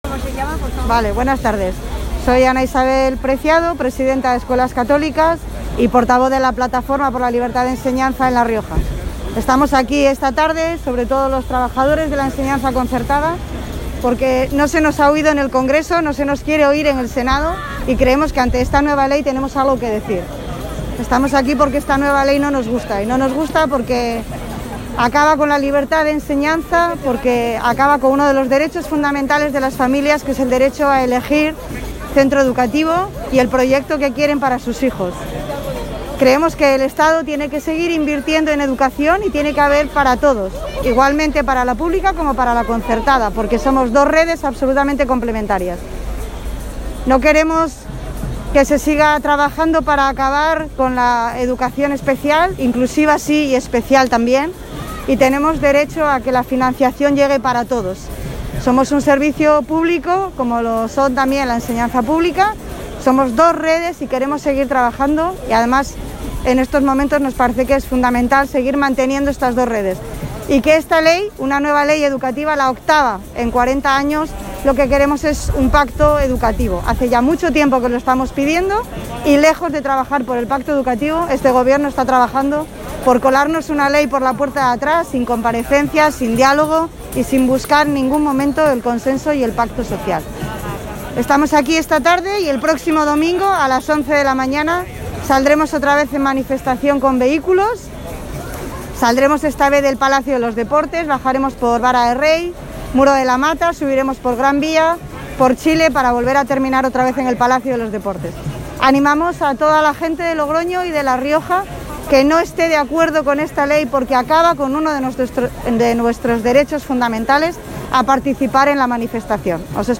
Más de 400 personas, entre docentes, familias, alumnos y titulares de centros, se han concentrado frente a la Delegación del Gobierno en La Rioja, para pedir la protección y la continuidad de la pluralidad democrática de nuestro sistema educativo actual.
Declaraciones